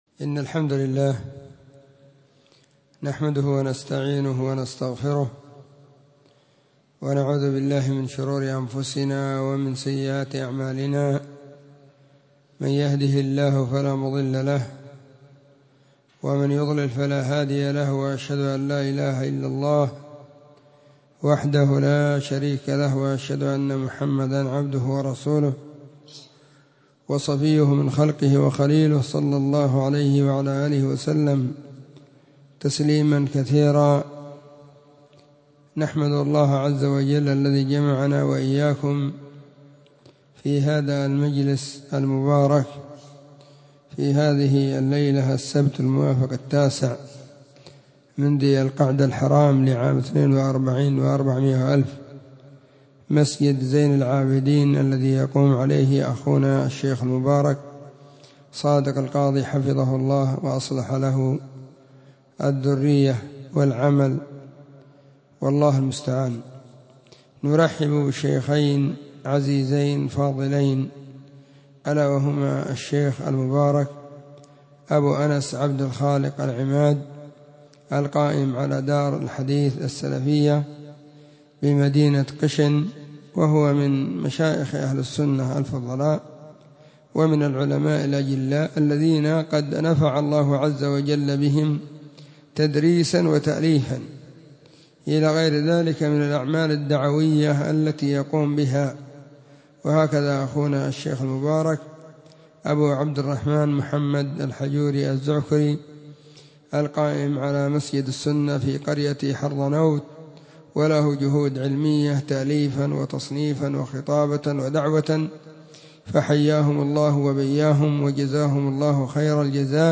السبت 9 ذو القعدة 1442 هــــ | كلمــــات | شارك بتعليقك